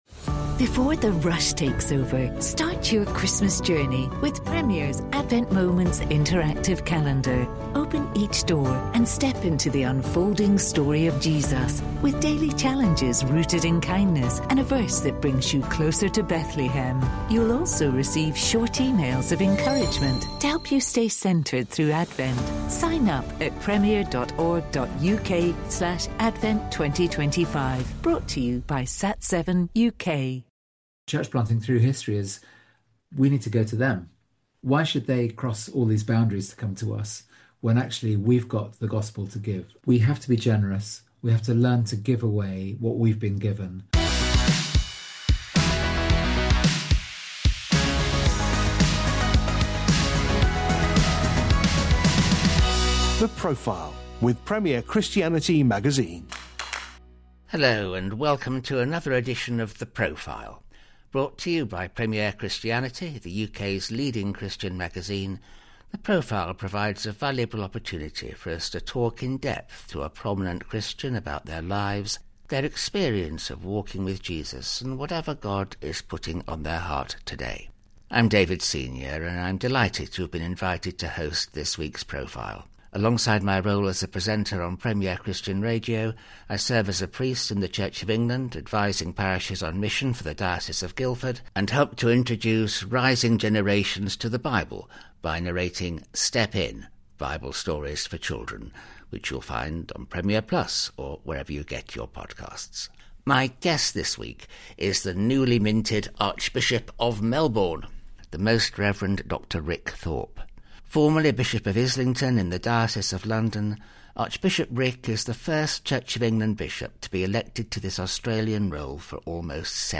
Hear the faith stories of a huge range of church leaders, musicians, preachers and more, as they share how God has led and guided them through both valleys and mountain-top experiences. The Profile brings you in-depth interviews to help you learn from the wisdom and experiences of people who have gone all-in for God.